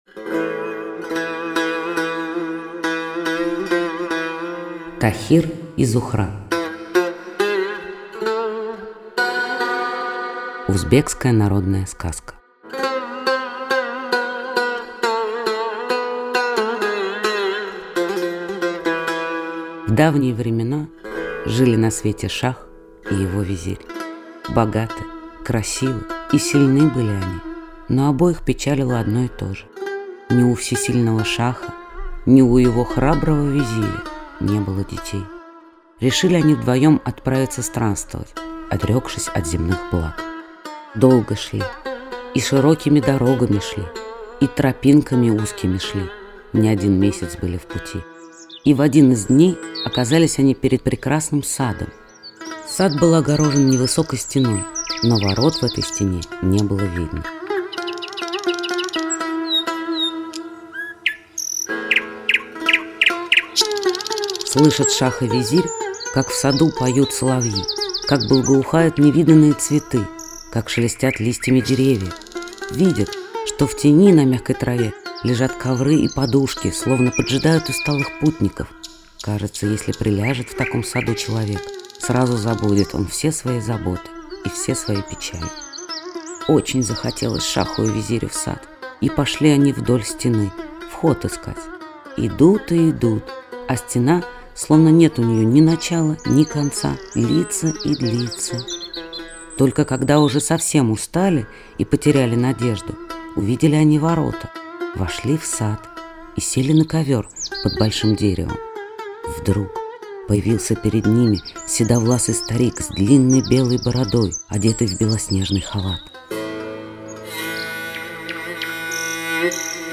Тахир и Зухра - узбекская аудиосказка - слушать онлайн